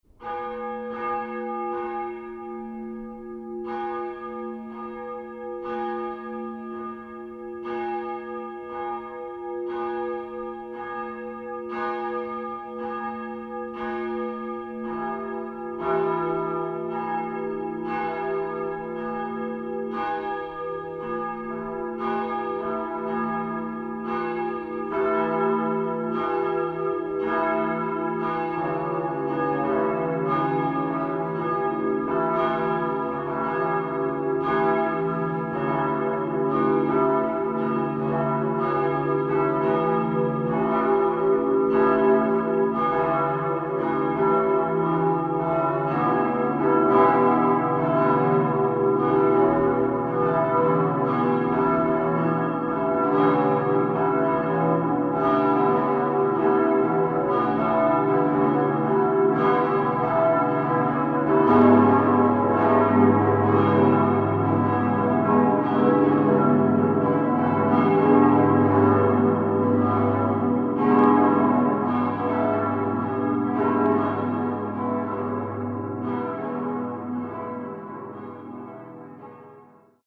Neue Kirchenmusik.
Die mittels Computer-Software-Technologien realisierten Audio-Rohprodukte werden anhand von H�rbeispielen vorgestellt.
muenstervollgelaeute_schnitt-101_web-00.mp3